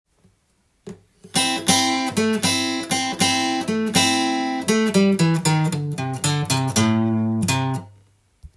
Aのブルースでも弾けるようにkeyを変換してみました.
ペンタトニックスケールを和音にして更にクオーターチョーキングも
ブルースの相性抜群ですのでAにブルースで使用可能です。